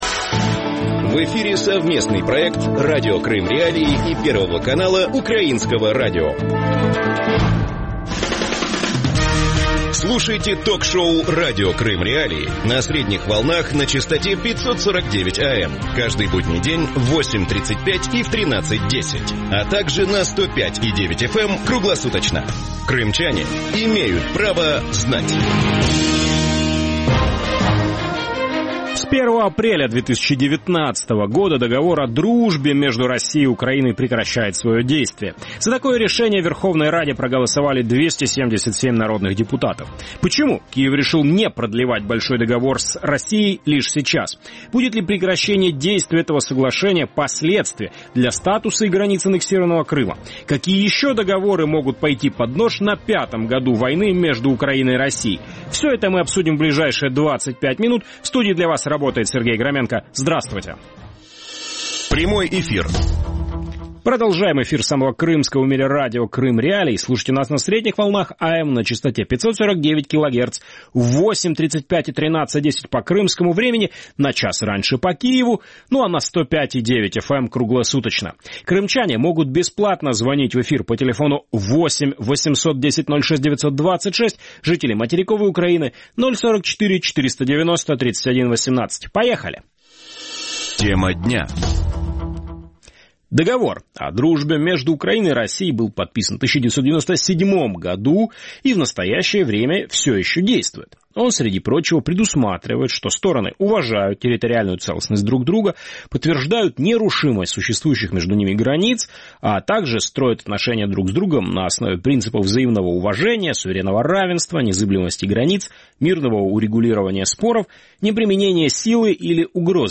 Почему Киев решил разорвать «большой» договор с РФ лишь сейчас? Будет ли иметь разрыв этого соглашения последствия для статуса и границ аннексированного Крыма? Какие еще договоры могут «пойти под нож» на пятом году войны между Украиной и Россией? Гости эфира